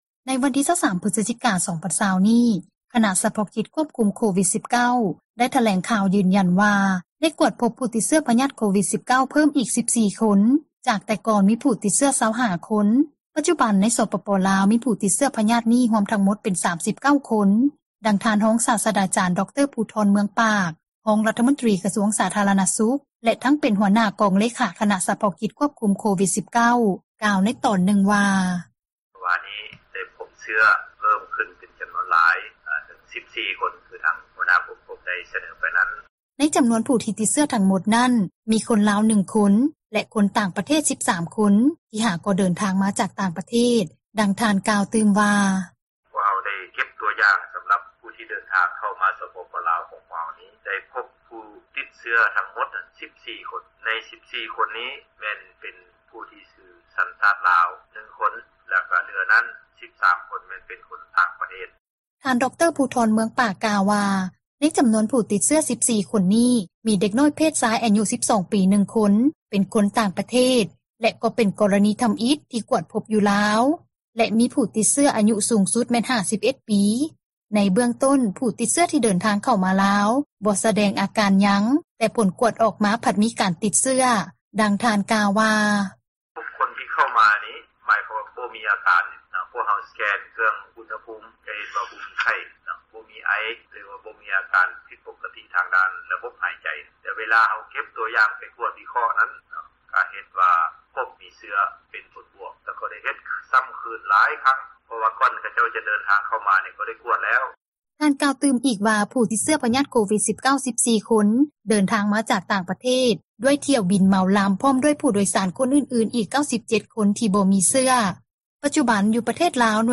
ໃນວັນທີ 23 ພຶສຈິກາ 2020 ນີ້, ຄະນະສະເພາະກິຈ ຄວບຄຸມໂຄວິດ-19 ໄດ້ຖແລງຂ່າວ ຢືນຢັນວ່າ ໄດ້ກວດພົບ ຜູ້ຕິດເຊື້ອພຍາດ ໂຄວິດ-19 ເພີ່ມອີກ 14 ຄົນ ຈາກແຕ່ກ່ອນ ມີຜູ້ຕິດເຊື້ອ 25 ຄົນ. ປັດຈຸບັນ, ໃນສປປລາວ ມີຜູ້ຕິດພຍາດນີ້ ຮວມທັງໝົດ ເປັນ 39 ຄົນ, ດັ່ງທ່ານຮອງສາສດາຈານ ດຣ ພູທອນ ເມືອງປາກ, ຮອງຣັຖມົນຕຣີ ກະຊວງສາທາຣະນະສຸຂ ແລະ ທັງເປັນຫົວໜ້າ ກອງເລຂາ ຄະນະສະເພາະກິຈ ຄວບຄຸມໂຄວິດ-19  ກ່າວຕອນນຶ່ງວ່າ: